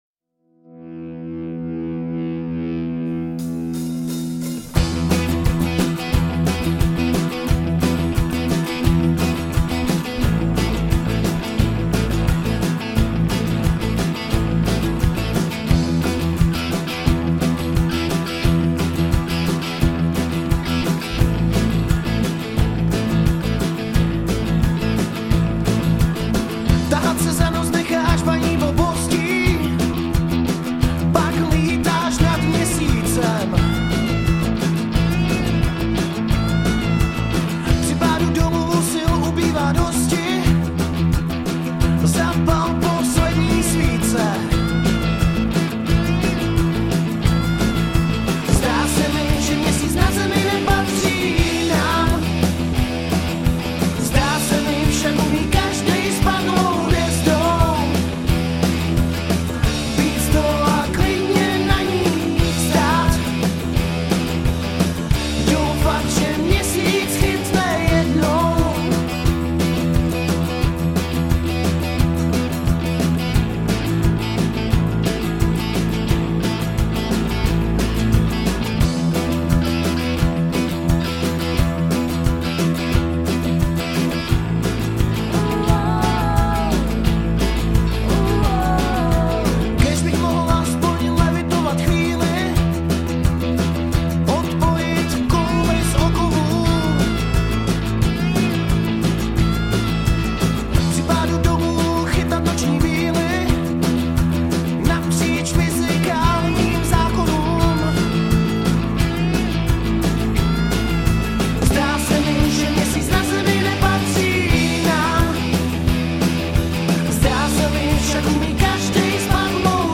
Žánr: Rock
pop/rockové kapely